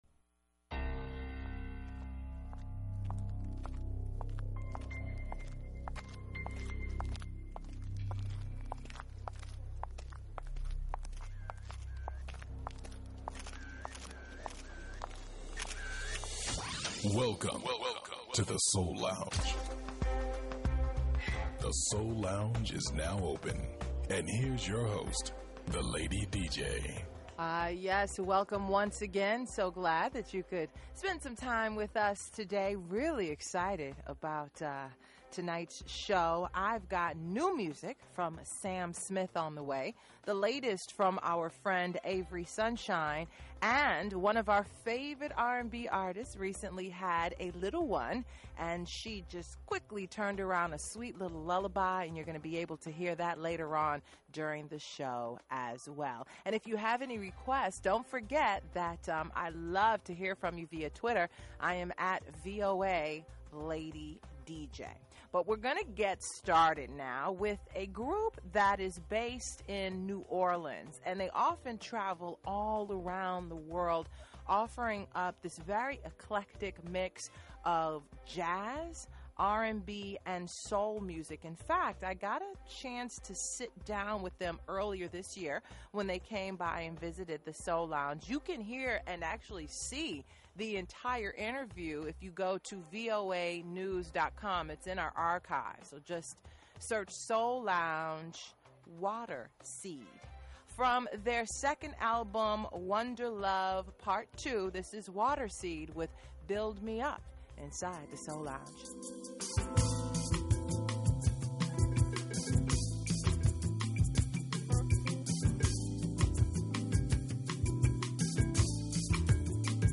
Classic Soul